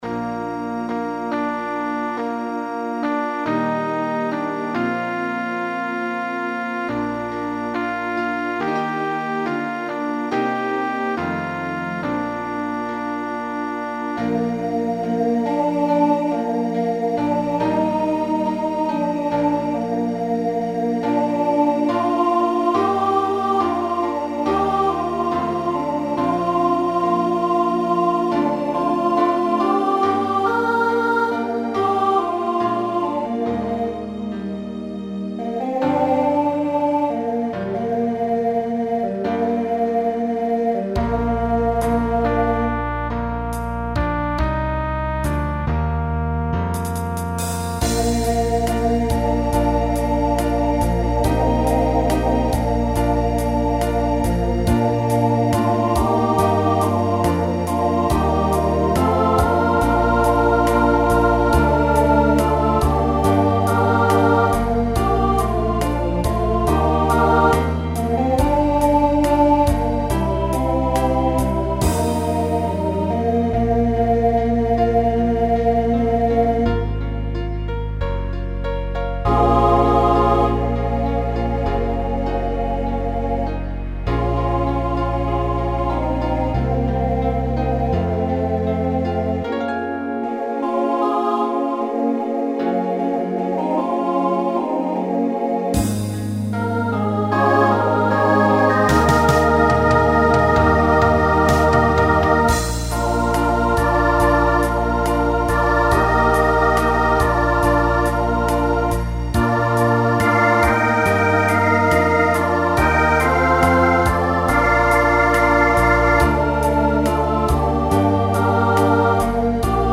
Genre Broadway/Film , Pop/Dance
Ballad Voicing SATB